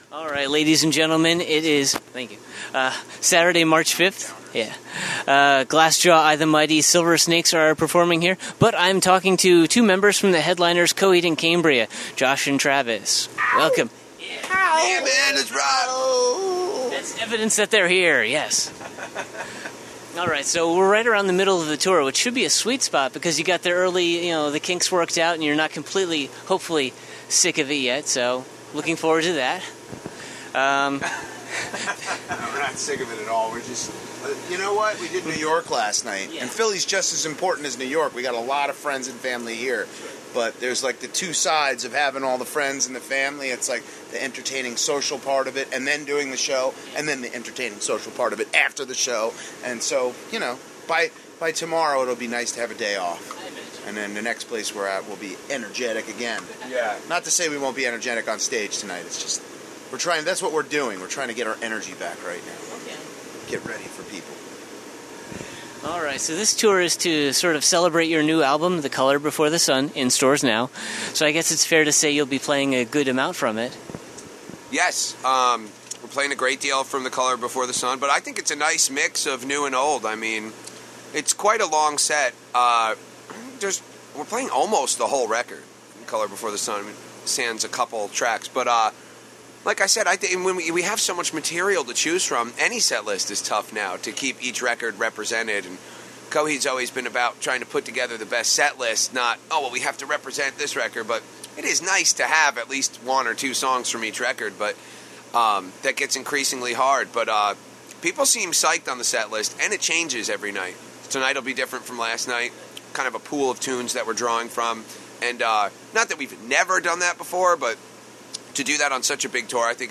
EXCLUSIVE: Coheed and Cambria INTERVIEW
67-interview-coheed-cambria.mp3